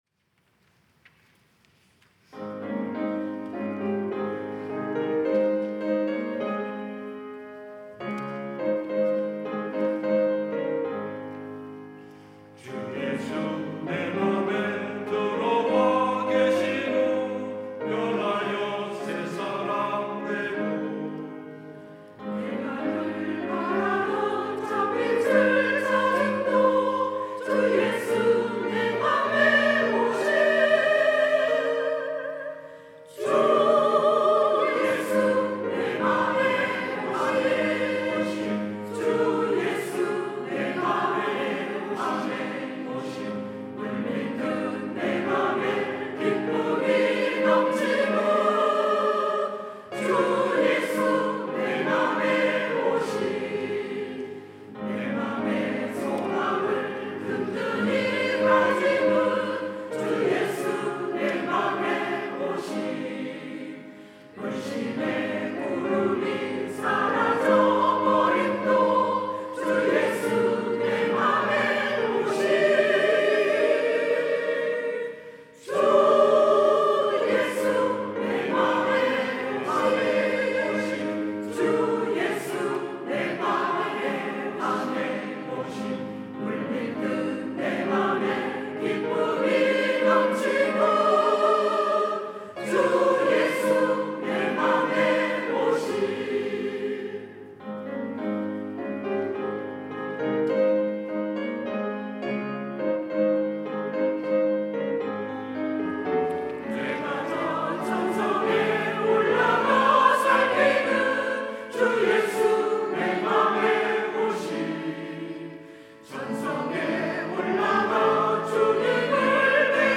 천안중앙교회
찬양대 가브리엘